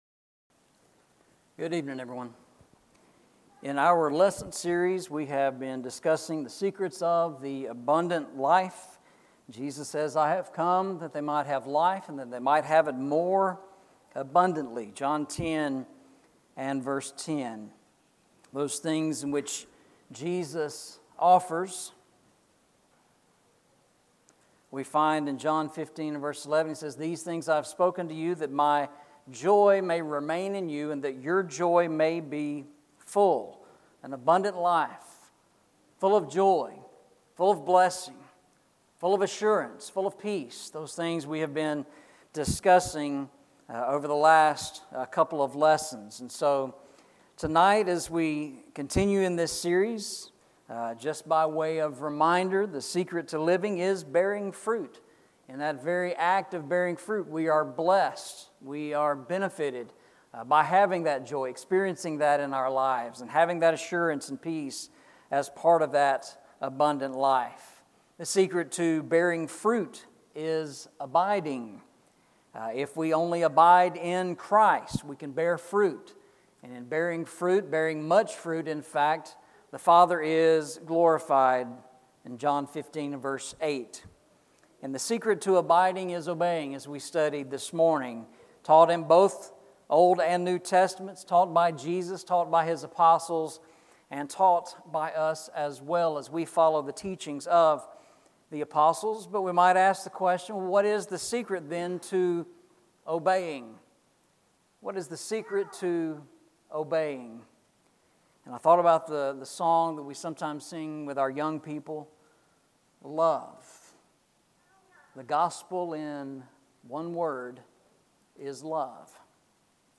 Eastside Sermons
John 15:15 Service Type: Sunday Evening « The Secret of Abiding Sermon on the Mount